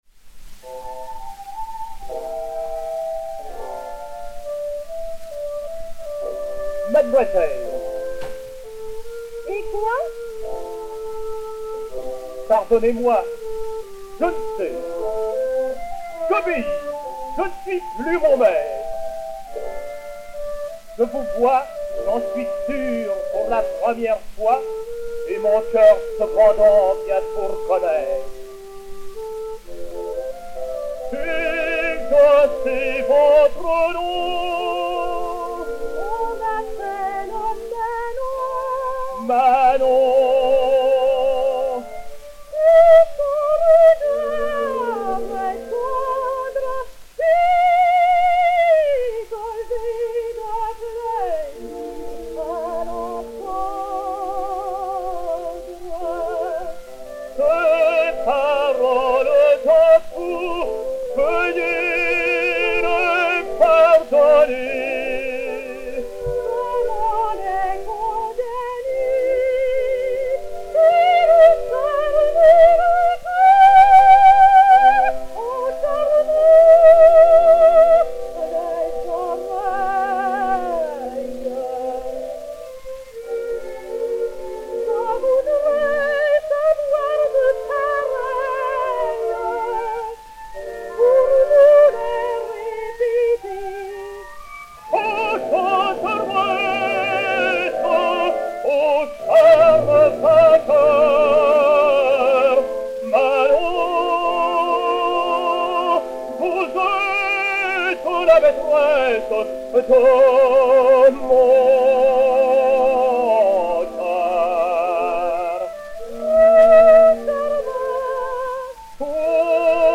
et Orchestre
Disque Pour Gramophone G.C.-34151, mat. 5794o, enr. à Paris en 1906